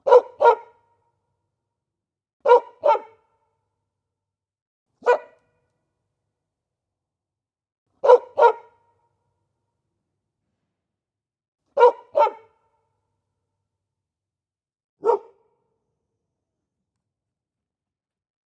Catégorie Animaux